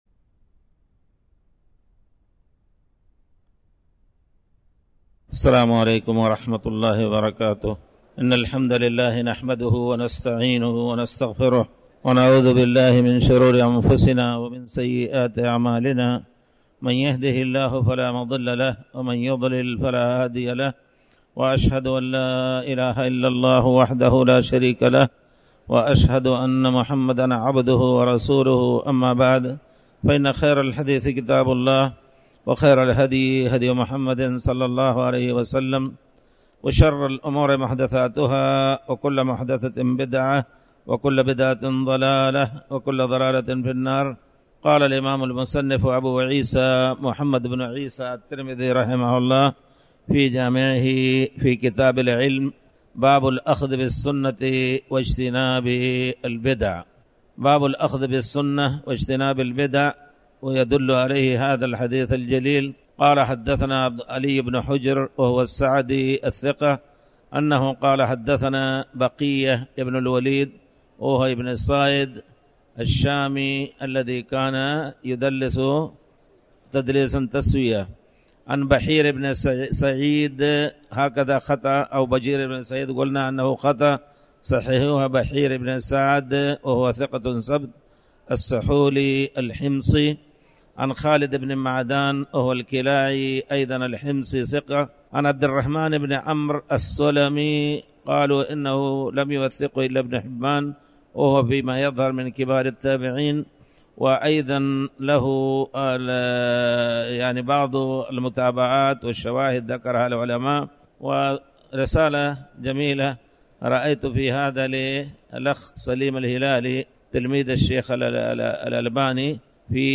تاريخ النشر ٢ شعبان ١٤٣٩ هـ المكان: المسجد الحرام الشيخ